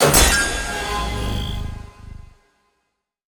Armor_Depleted.wav